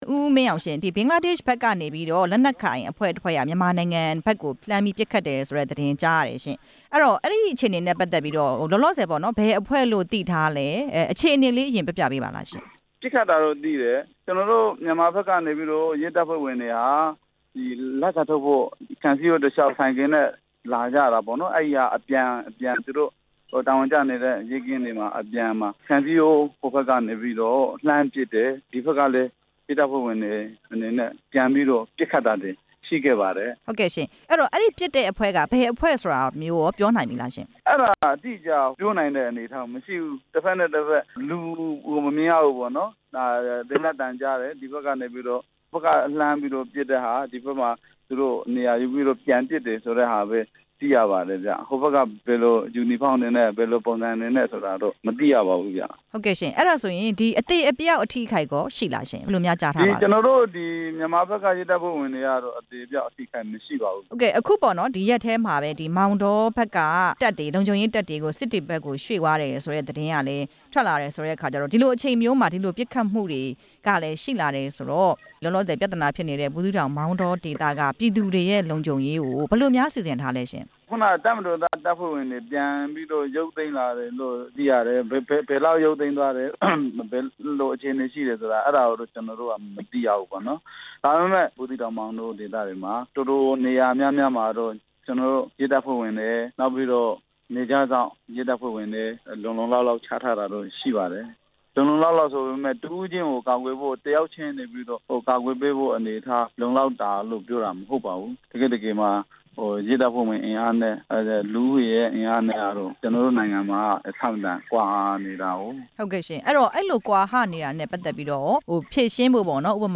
ဘင်္ဂလားဒေ့ရှ်နယ်စပ်မှာ မြန်မာရဲတပ်သားတွေ ပစ်ခတ်ခံရမှု မေးမြန်းတင်ပြချက်